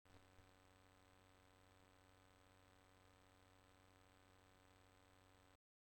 SSL 12: Rauschen bei Line Inputs
Das Rauschen entsteht schon wenn der Synth nichts spielt und der Line Eingang ausgewählt ist).